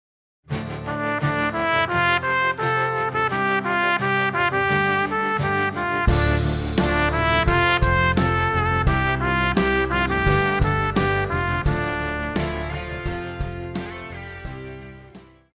Pop
Trumpet
Band
Instrumental
Punk
Only backing